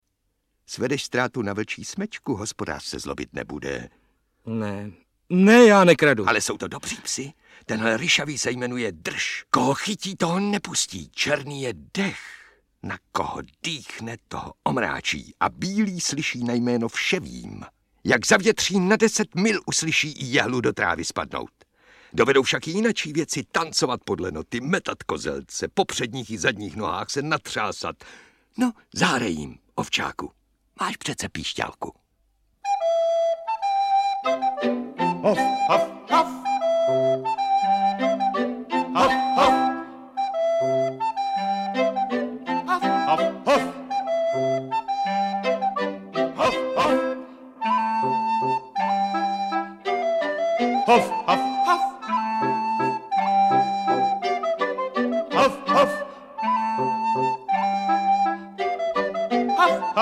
Audiobook
Read: Taťjana Medvecká